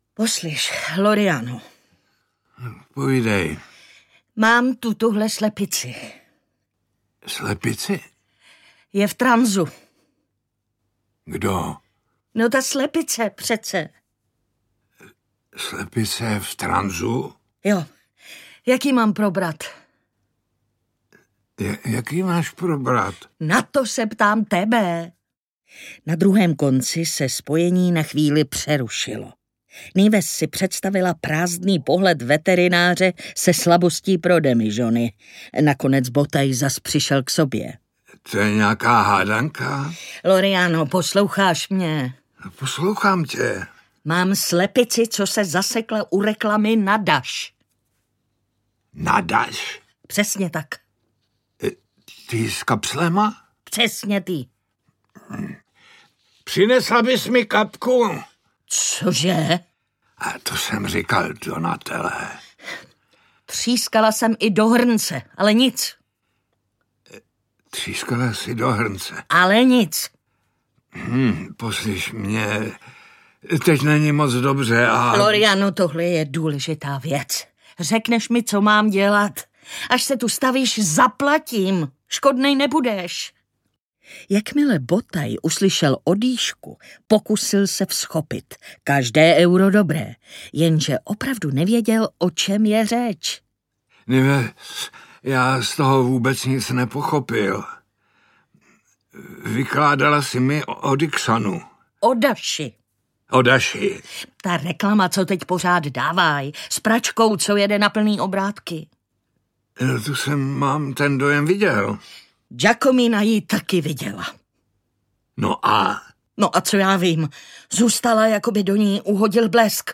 Nives, co to povídáš? audiokniha
Ukázka z knihy